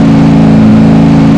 f350_low.wav